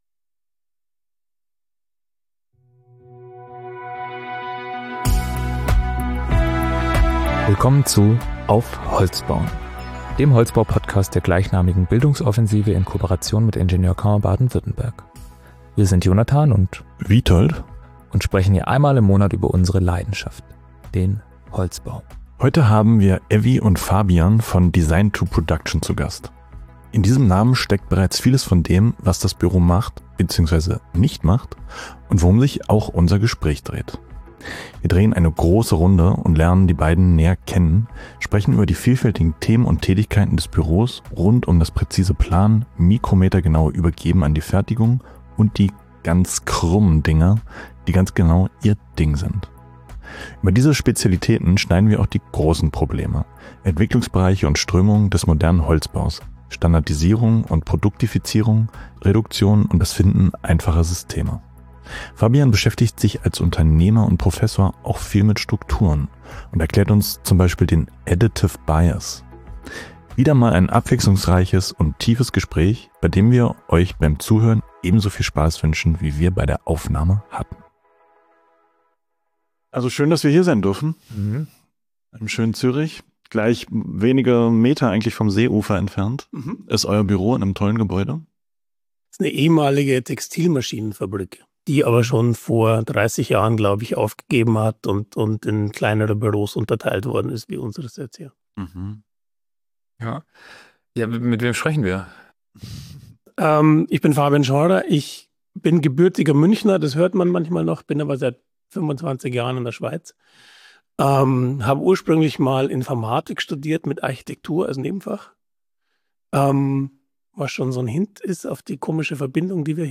Wieder mal ein abwechslungsreiches und tiefes Gespräch, bei dem wir euch beim Zuhören ebenso viel Spaß wünschen, wie wir bei der Aufnahme hatten.